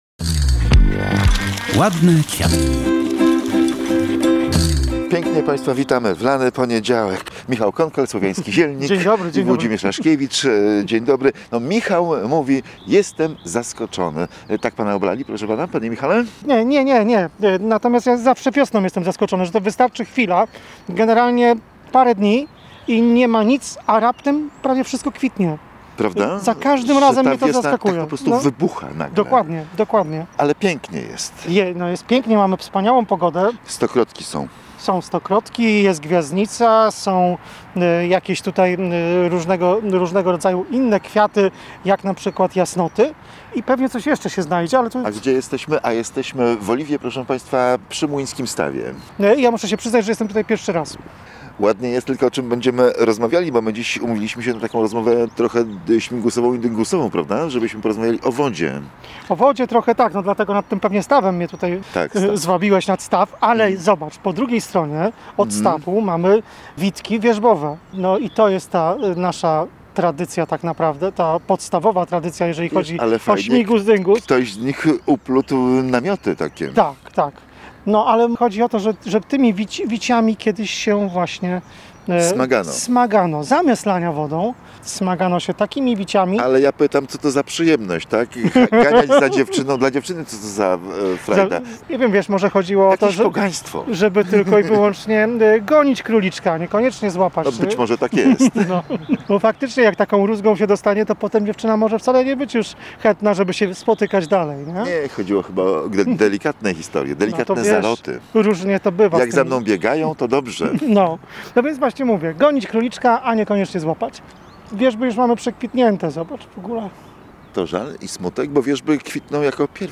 W poniedziałek wielkanocny padło na rozmowę o wodzie.